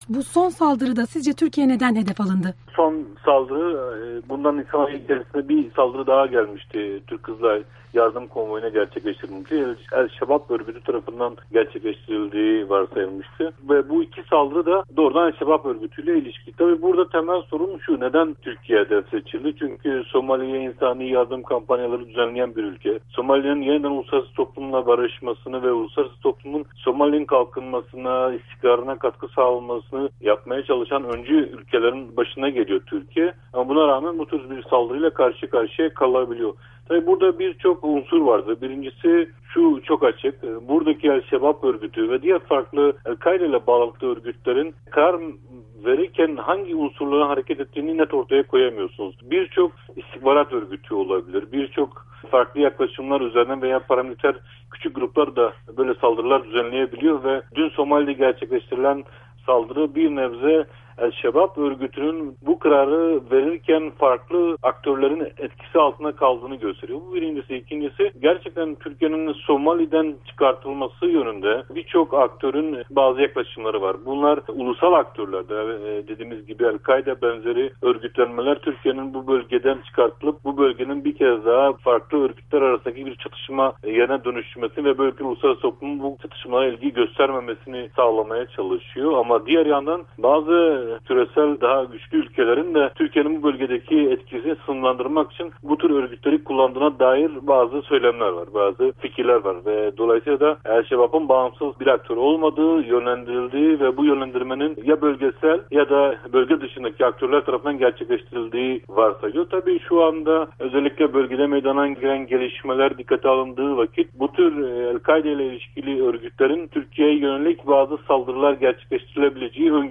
söyleşiyi